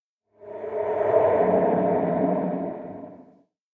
cave13.ogg